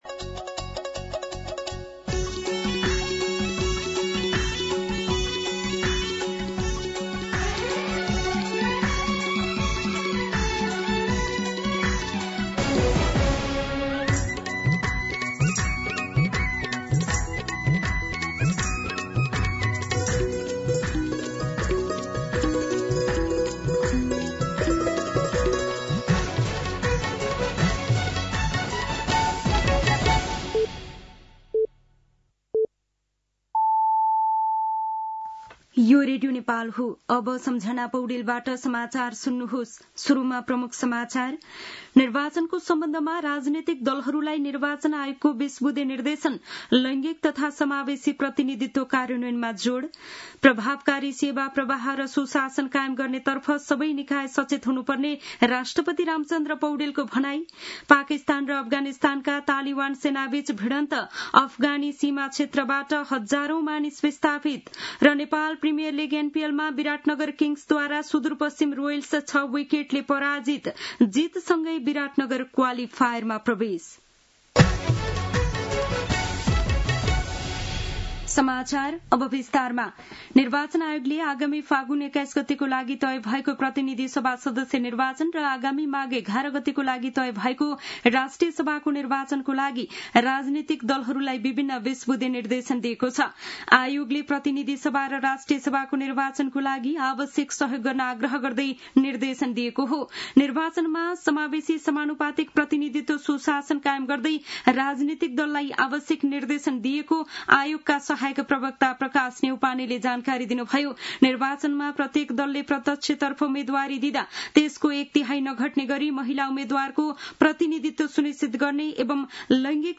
दिउँसो ३ बजेको नेपाली समाचार : २० मंसिर , २०८२
3-pm-Nepali-News-1.mp3